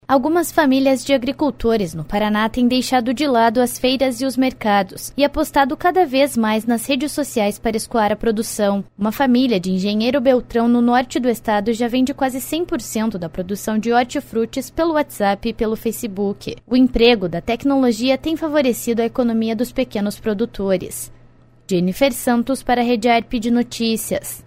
12.07 – BOLETIM SEM TRILHA – Agricultores familiares do Paraná apostam nas redes sociais para vender a produção